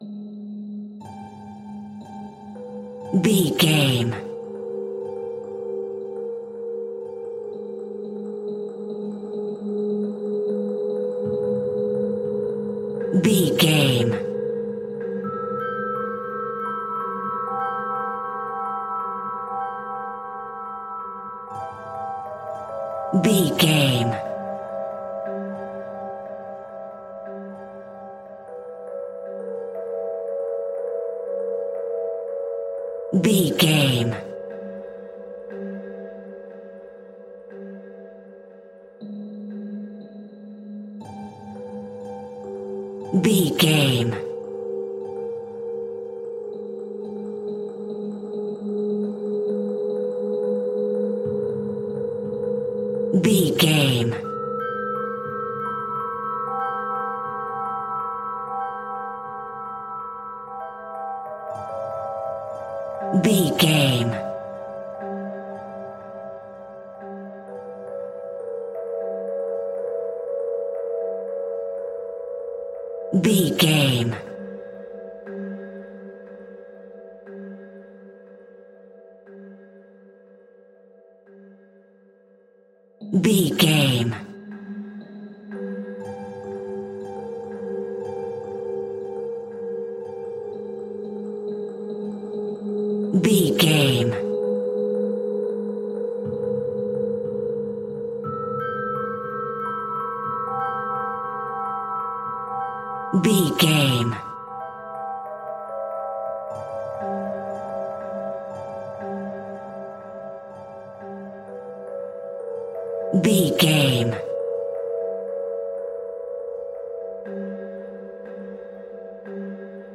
Scary Music Box.
Atonal
ominous
haunting
eerie
synthesizer
creepy
Horror Synth Ambience